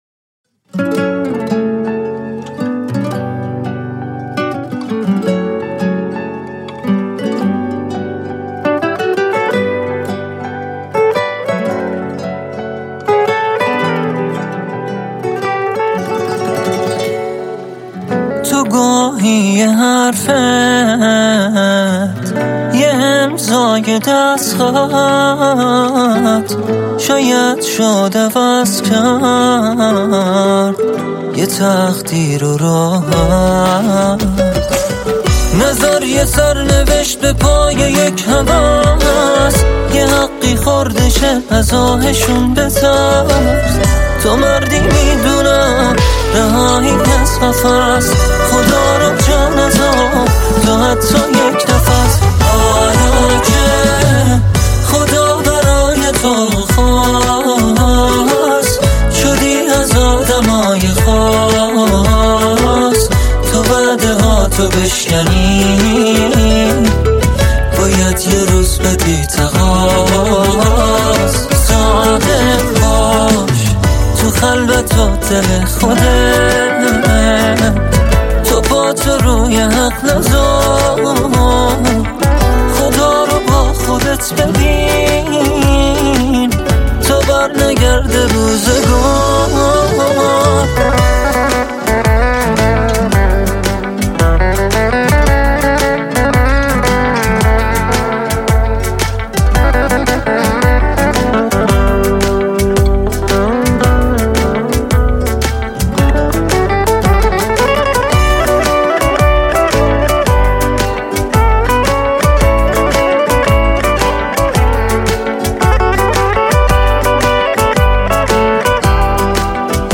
شاد